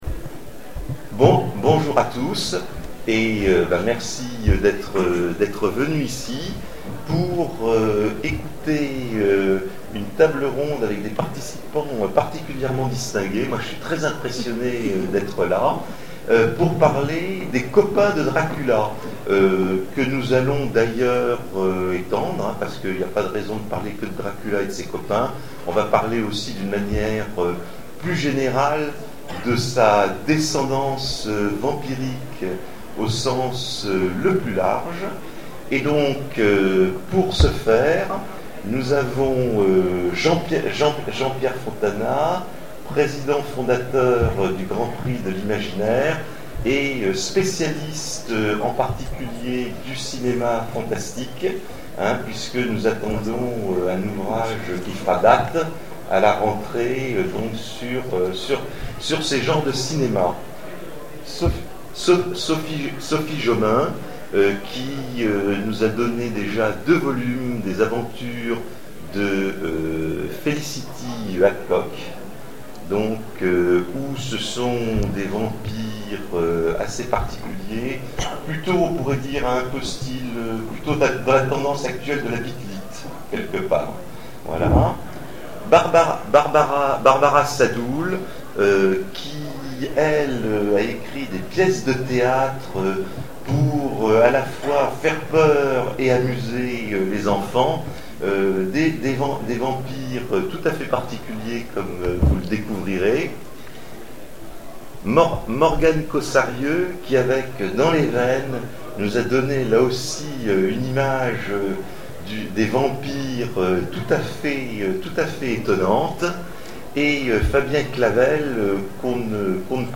Zone Franche 2013 : Conférence Les copains de Dracula